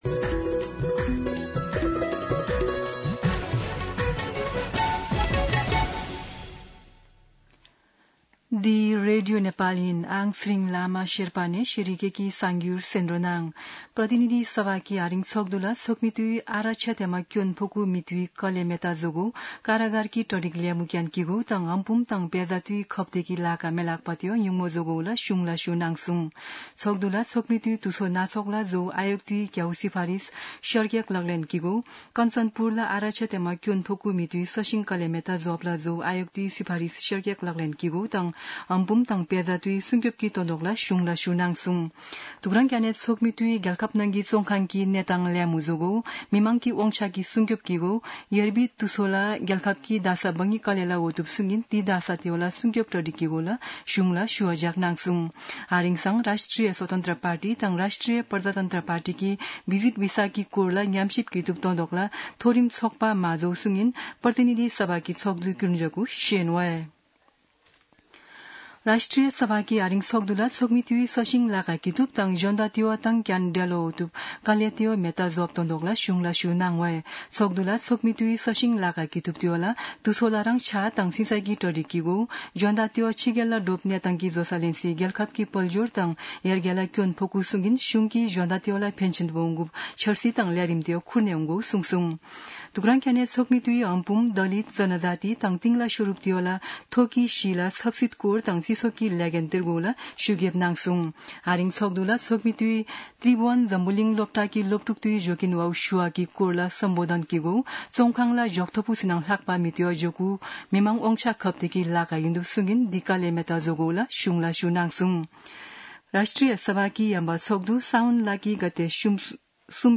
शेर्पा भाषाको समाचार : २७ साउन , २०८२
Sherpa-News-27.mp3